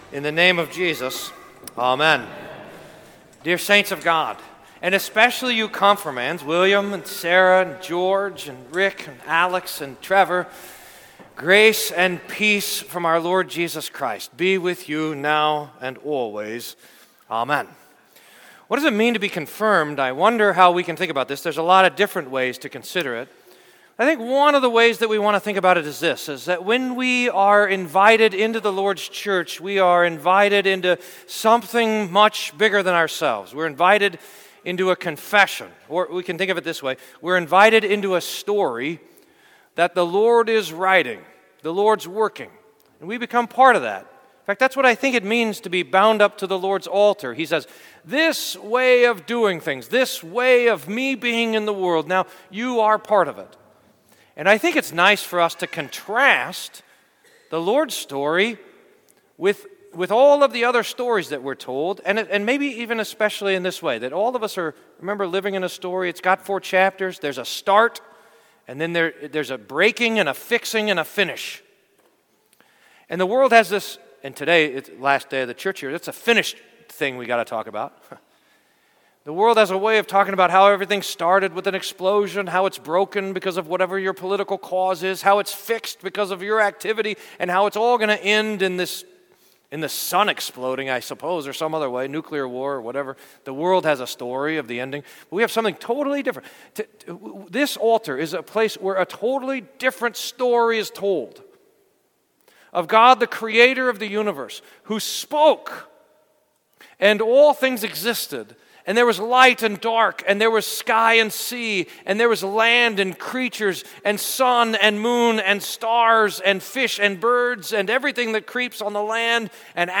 Sermon for Last Sunday of the Church Year